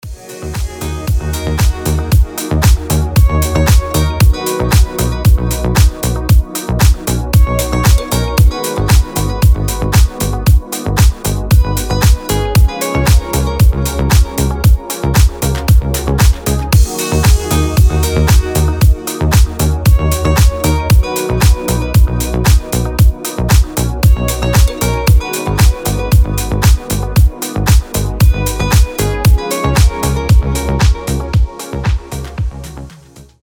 • Качество: 320, Stereo
гитара
deep house
без слов
красивая мелодия
Красивая мелодия со струнными